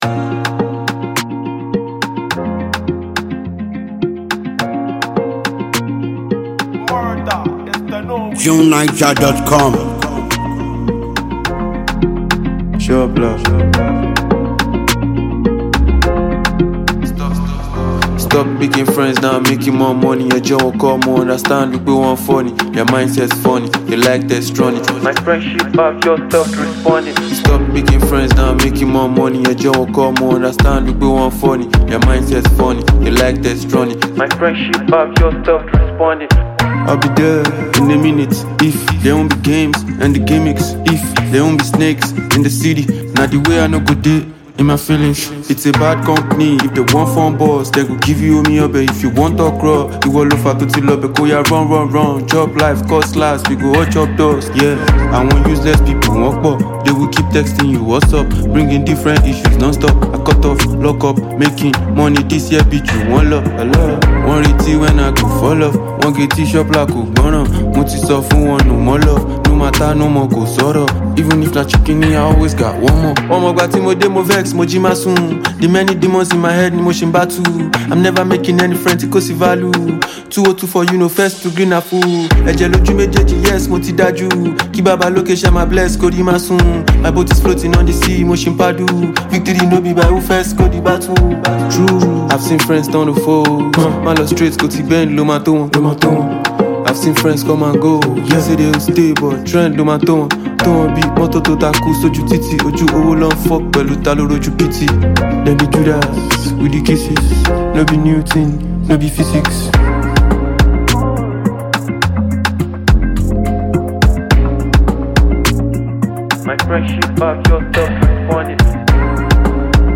a sensational Nigerian hip-hop music phenomenon